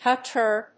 音節hau・teur 発音記号・読み方
/hɔːtˈɚː(米国英語), əʊtˈəː(英国英語)/